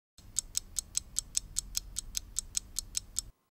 Clock Ticking Sound
household
Clock Ticking